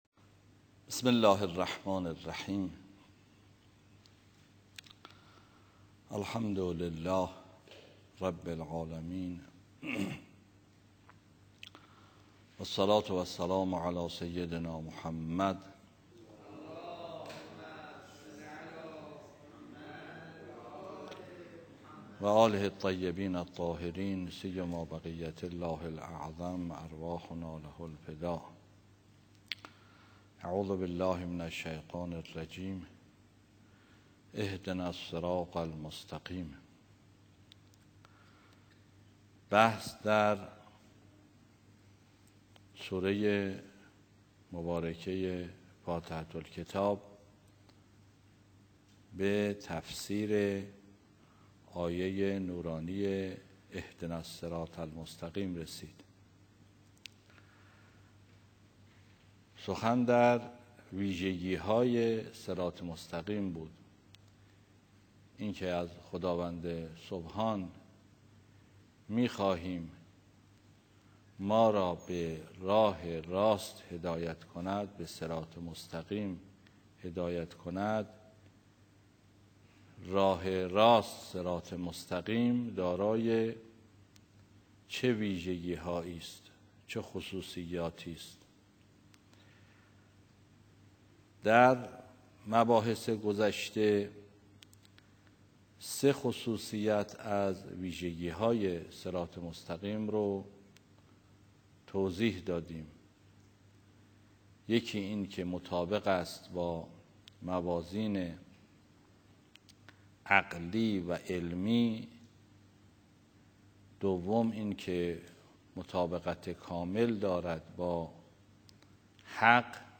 آیت‌الله ری‌شهری در جلسه تفسیر قرآن: